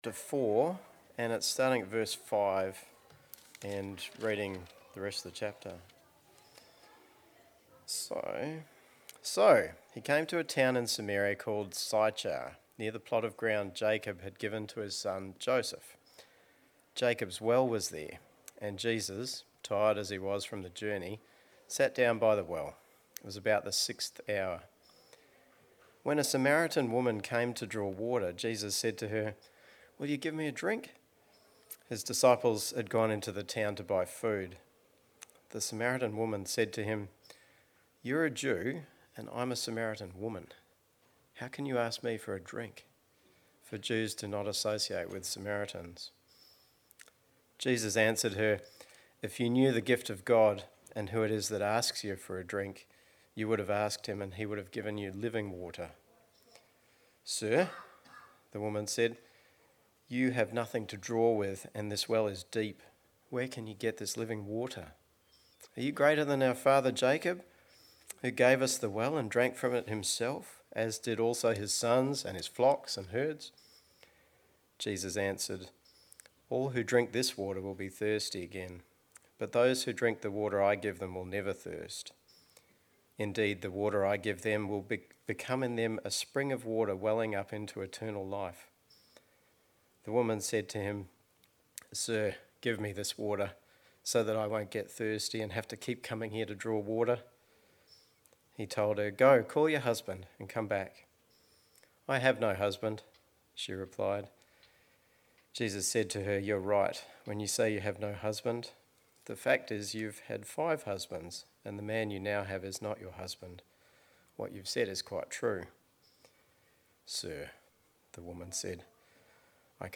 Sermons | All Saints Parish Palmerston North